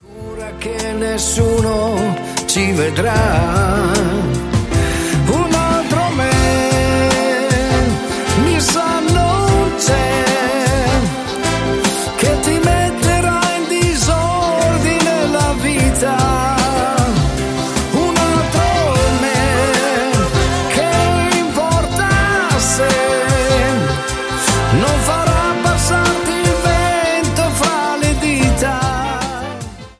SLOW  (03,40)